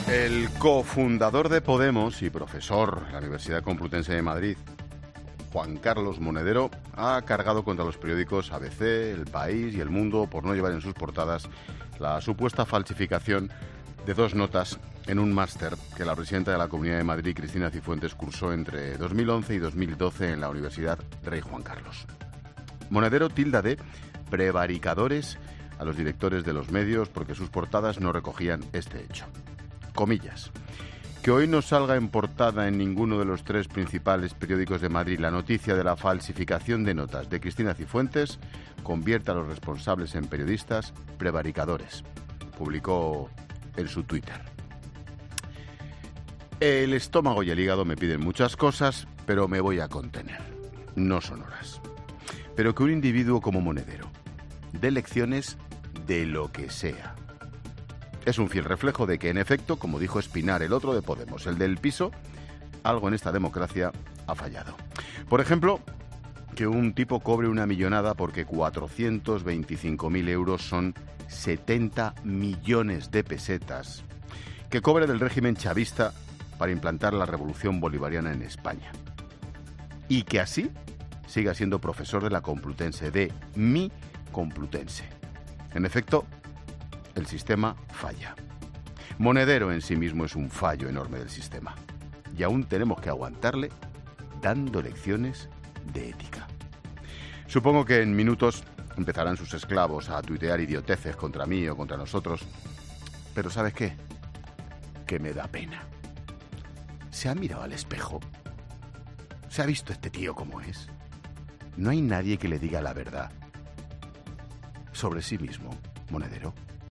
Monólogo de Expósito
Comentario de Ángel Expósito sobre la última polémica de Monedero.